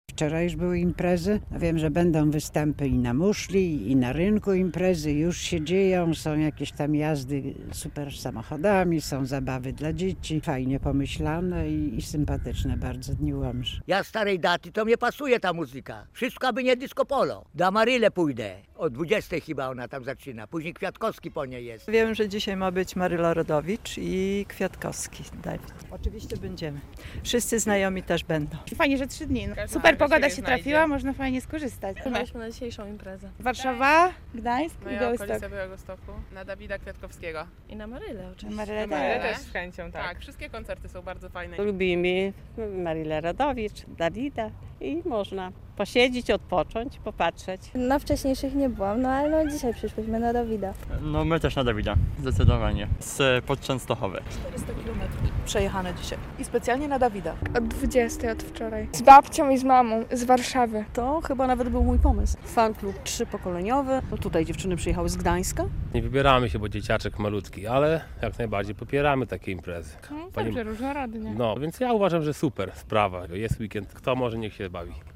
Dni Łomży, dzień drugi - relacja